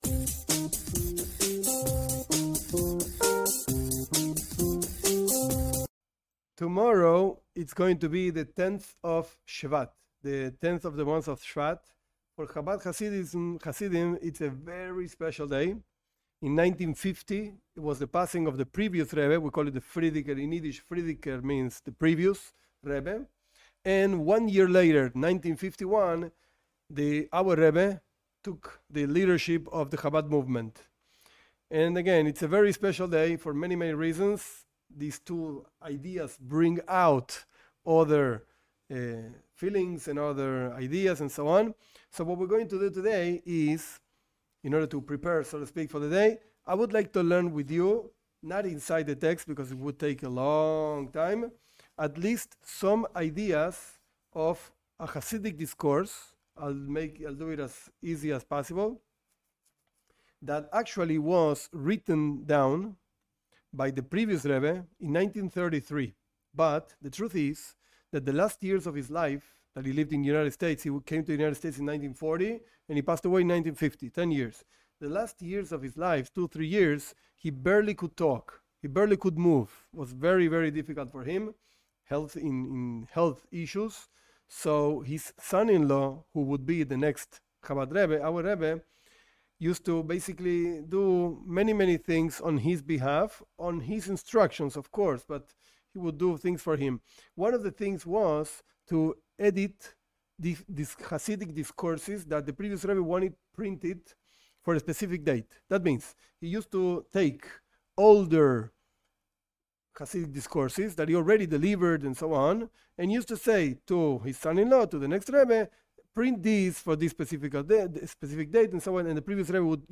This class summarizes the last chassidic discourse that the Previous Rebbe, Rabi Yosef Yitzchak Schneerson, of blessed memory, gave for printing, for the day of his passing.